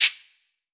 Perc (14).wav